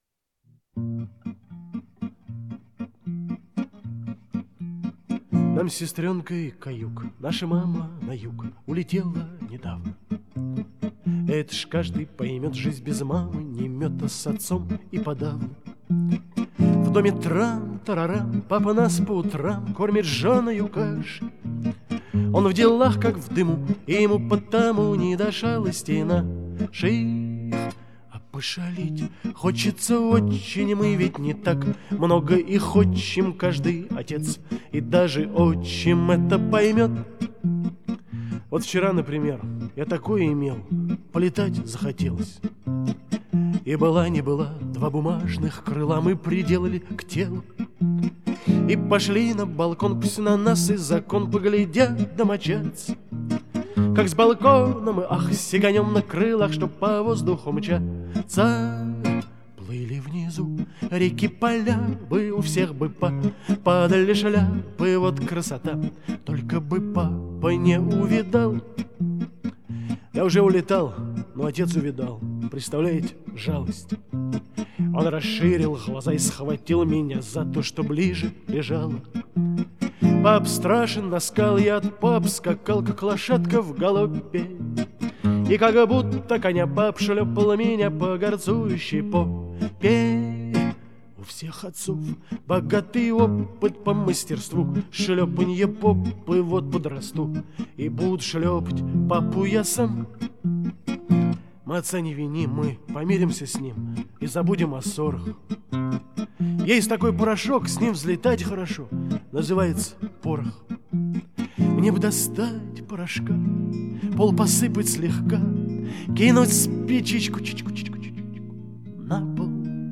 В исполнении автора